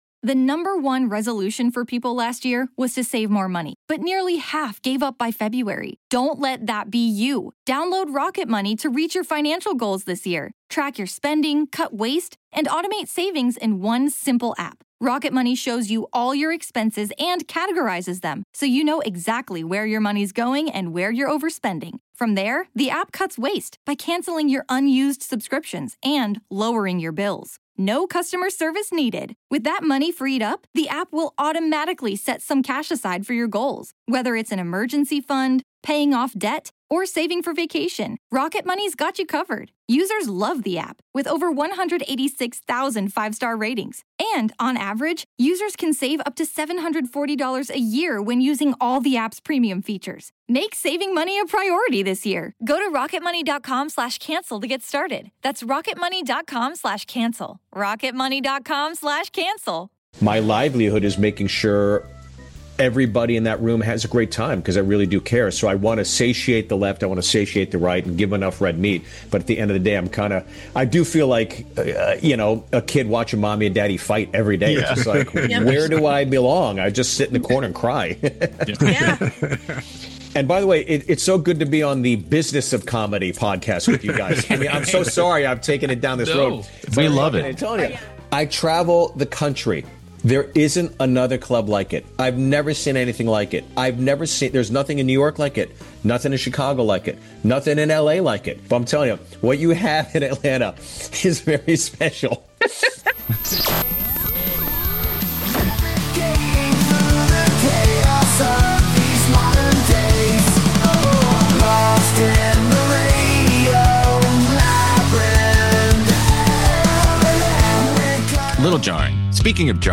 Steve Byrne Interview | Comedy, Filmmaking & Kill Tony
This week on the Radio Labyrinth Podcast, we kick things off with a special guest—comedian, actor, writer, and director Steve Byrne, who joins us ahead of his Atlanta shows at Punchline Comedy Club.